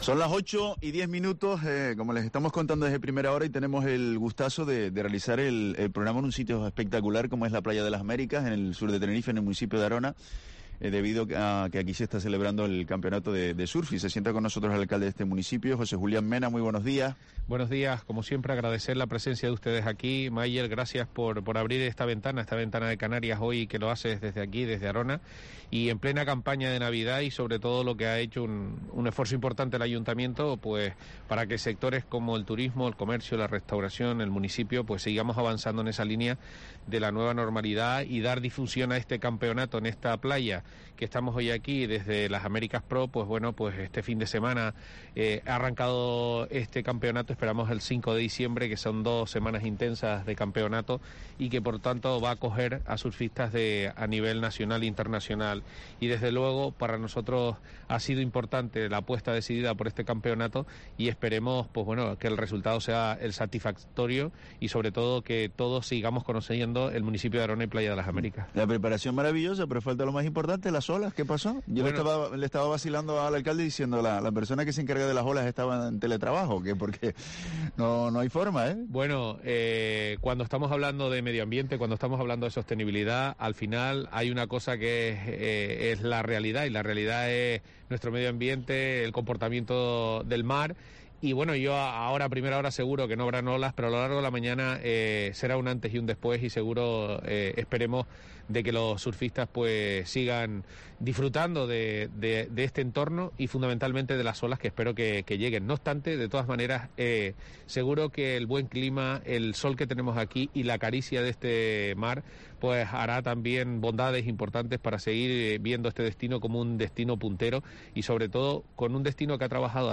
Entrevista a José Julián Mena, alcalde de Arona, en el Spring Surfest Las Américas Pro